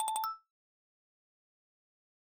new_event.ogg